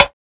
• '00s Sharp Acoustic Snare Sample C# Key 03.wav
Royality free snare tuned to the C# note. Loudest frequency: 3729Hz
00s-sharp-acoustic-snare-sample-c-sharp-key-03-4oe.wav